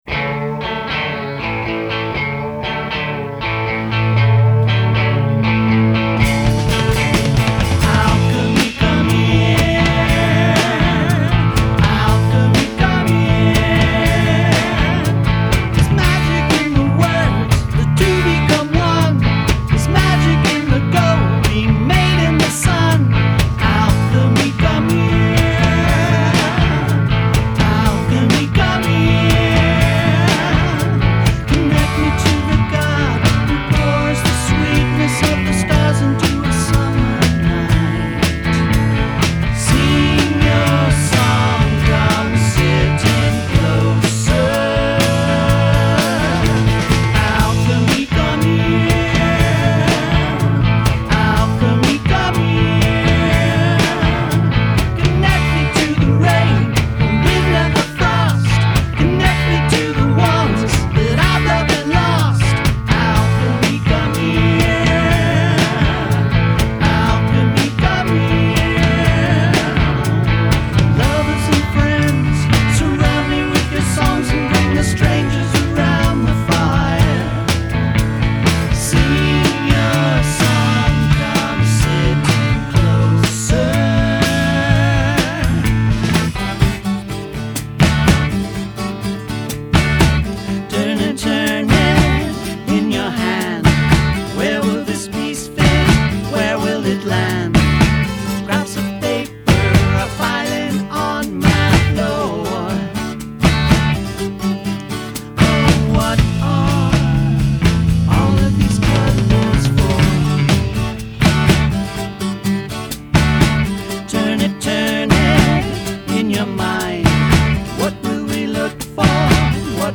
boppy